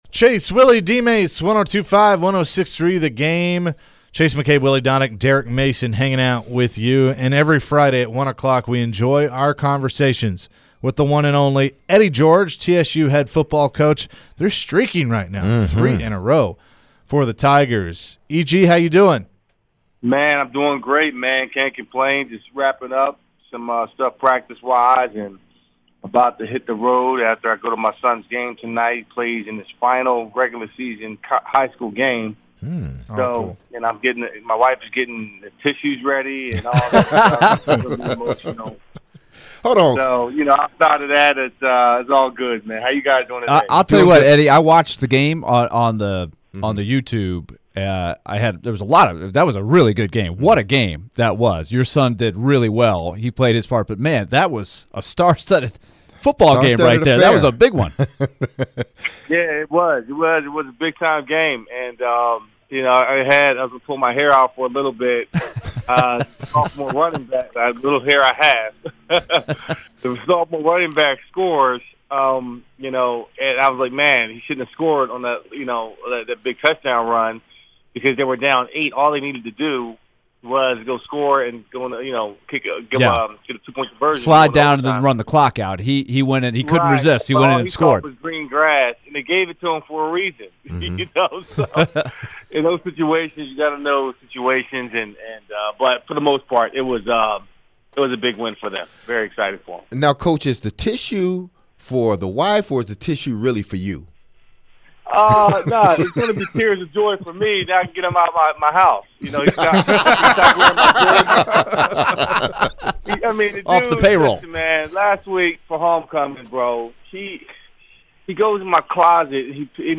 Eddie George interview (10-28-22)